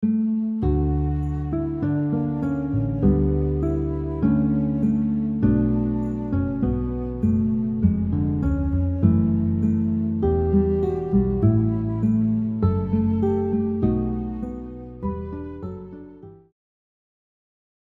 melody preview
• Guitar arrangement